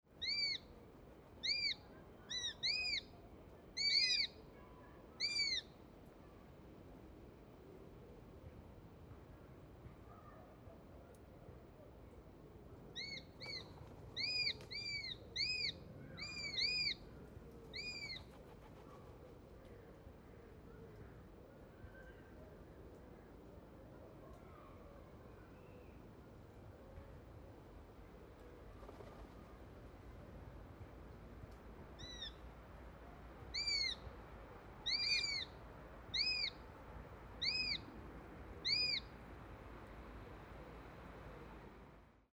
Erdei madarak / Madarak / Madárhangok / Ragadozó madarak
Karvaly (Accipiter nisus) hangja
Inkább rövid, éles, csipogó vagy vijjogó hangokból áll. A leggyakrabban hallható hangja egy gyors, ismétlődő „kik-kik-kik” vagy „kikikiki”, amit főként a nőstények hallatnak a fészek környékén, vagy amikor a fiókák élelmet kérnek.